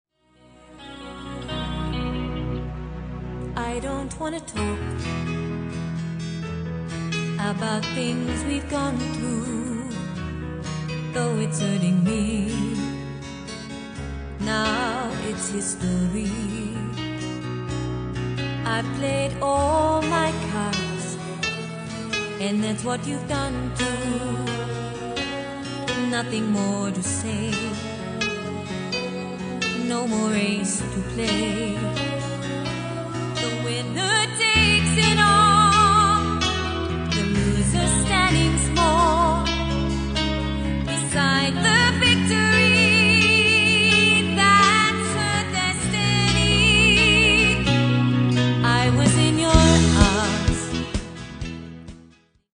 a 5 piece live band with 5 part harmonies